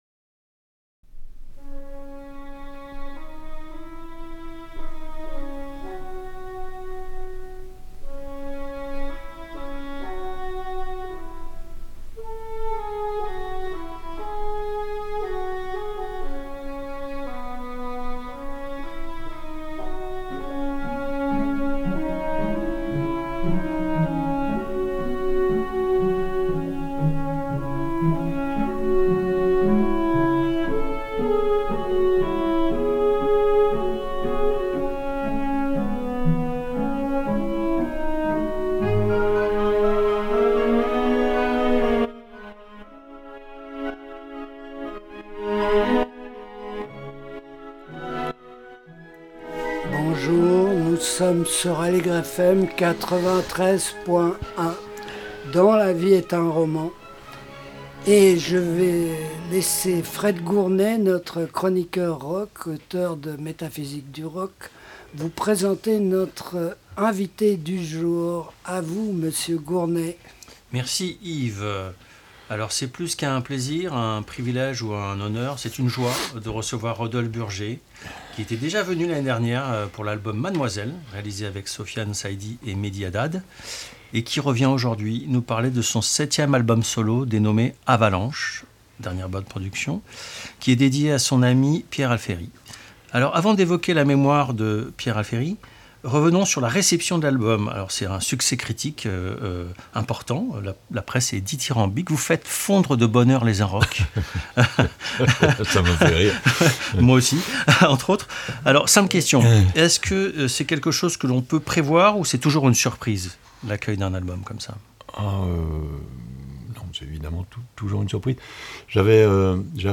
La vie est un roman # 21 janvier 2025 – Interview de Rodolphe Burger pour Avalanche l’album hommage à Pierre Alferi